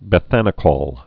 (bĕ-thănĭ-kôl, -kŏl)